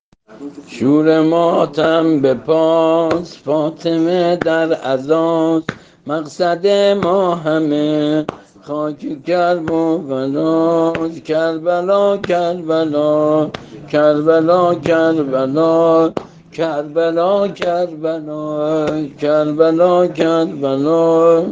◾نوحه‌ی‌شب اول محرم